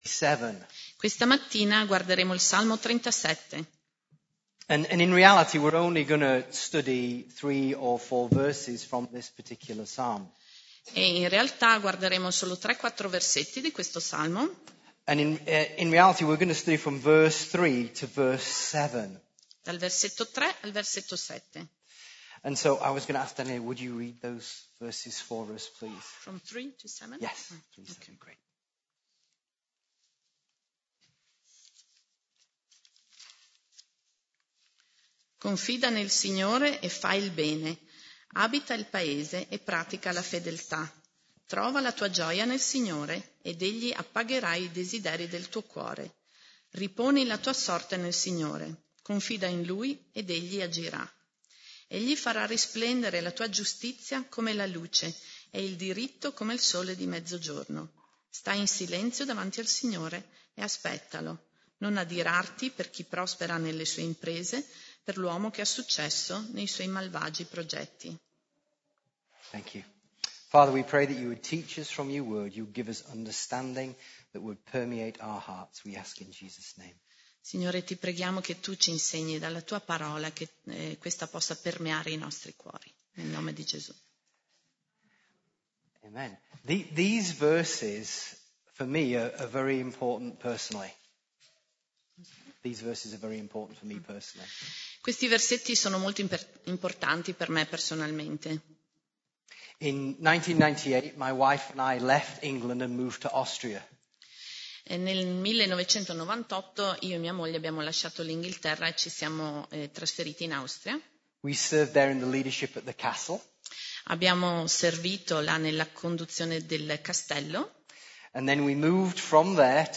Messaggio di Domenica 13 Ottobre